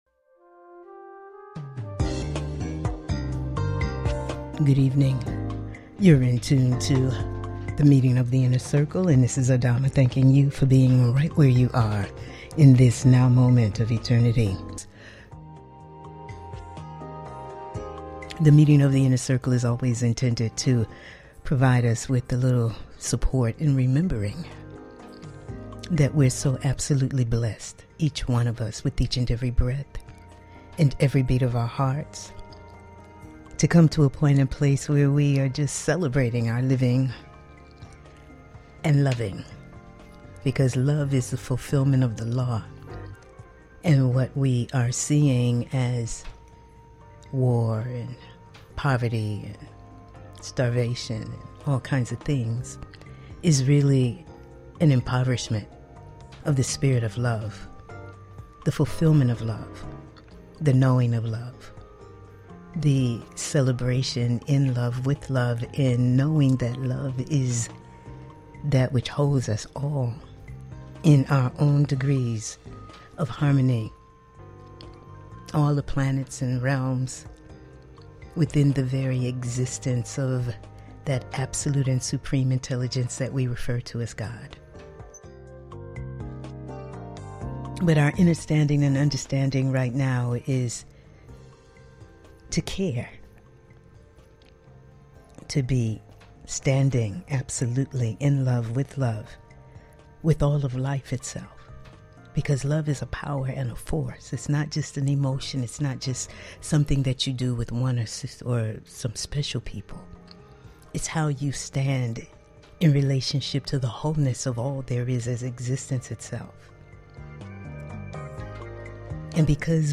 Talk Show
Weekly Show